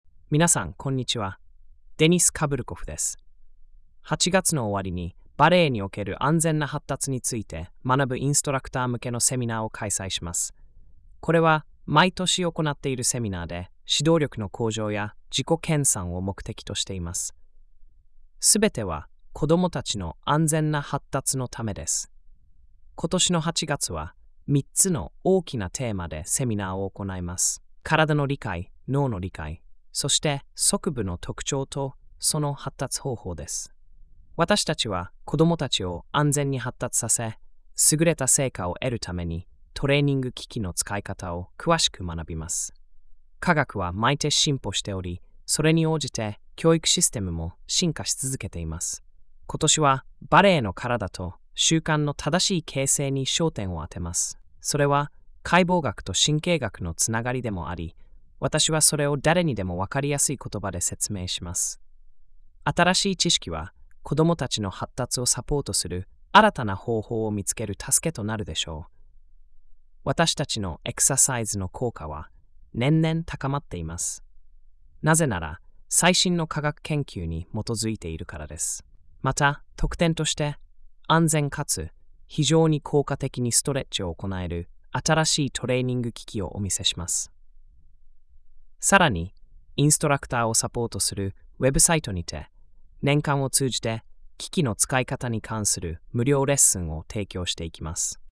ここをタップするとAIで作った日本語音声が聞けます。